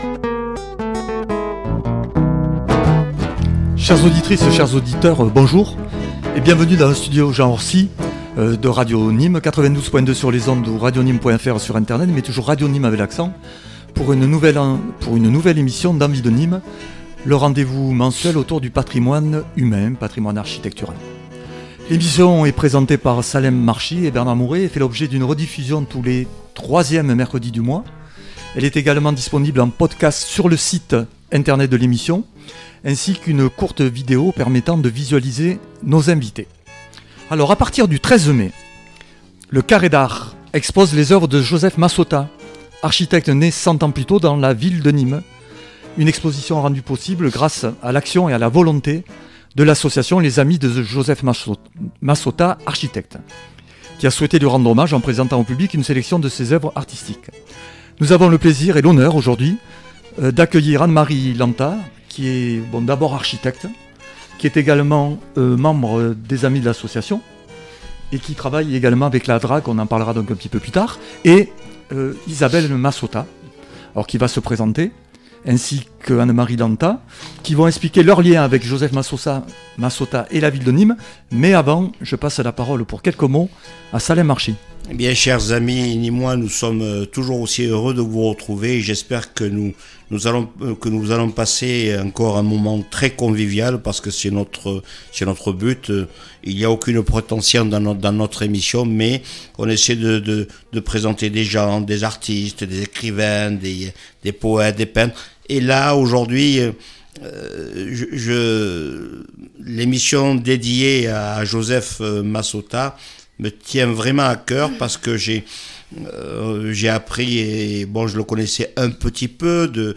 tous les premiers mercredis du mois de 15h00 à 16h00 en direct des studios